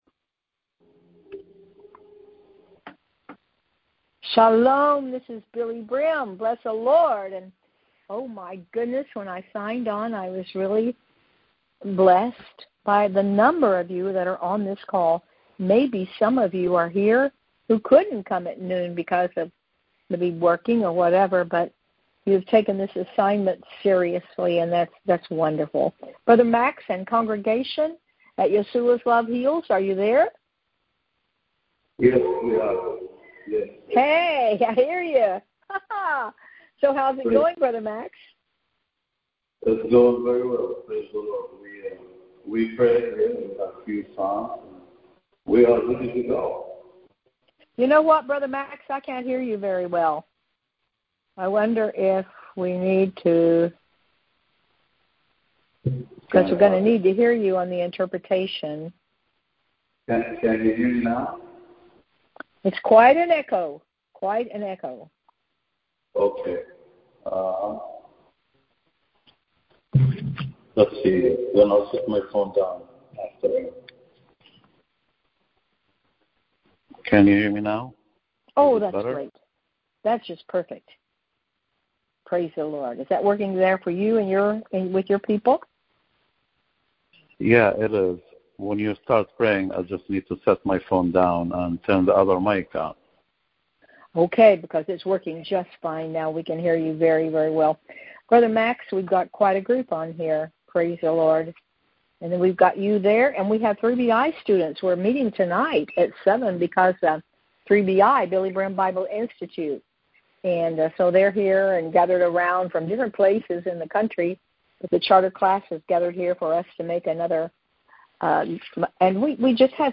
Wednesday Noon Prayer
The audio was recorded via our BBM Phone Cast system.